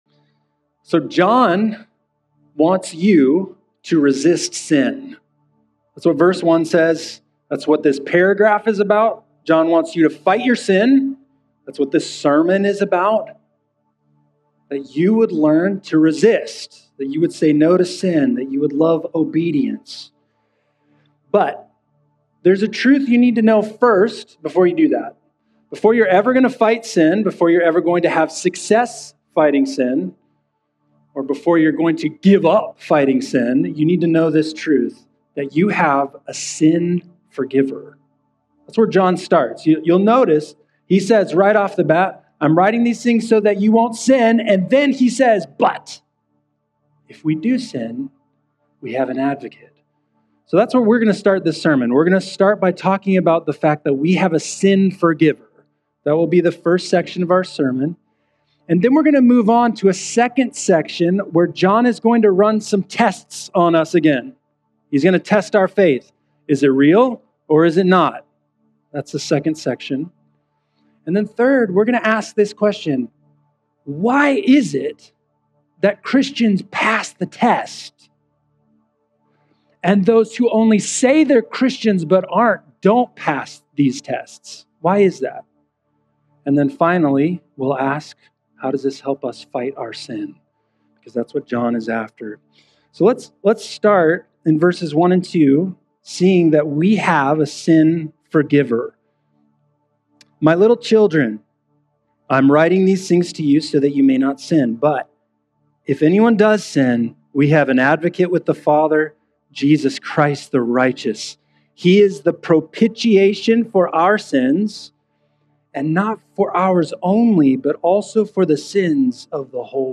sermon-may-31.mp3